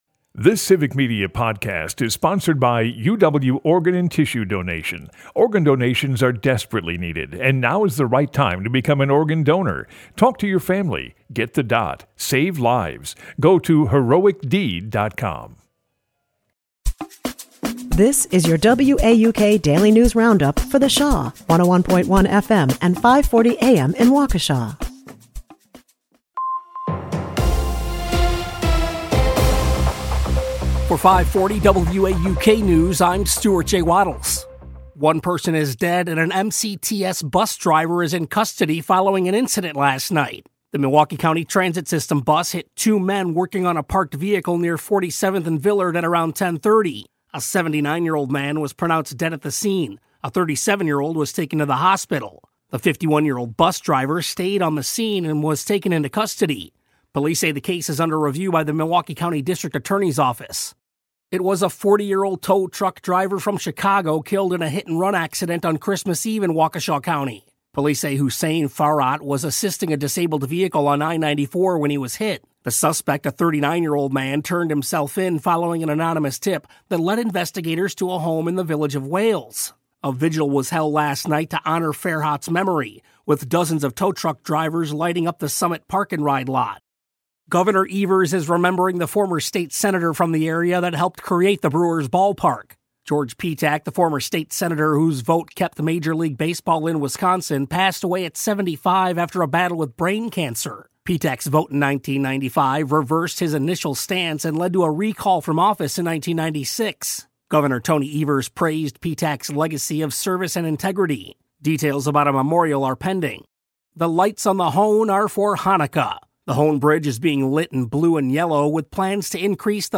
The WAUK Daily News Roundup has your state and local news, weather, and sports for Milwaukee, delivered as a podcast every weekday at 9 a.m. Stay on top of your local news and tune in to your community!